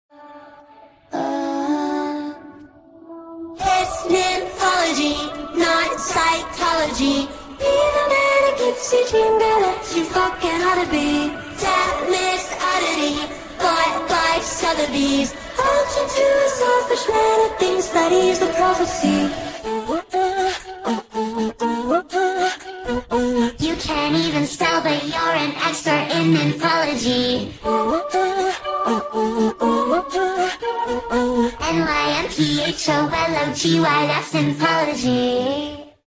ACAPELLA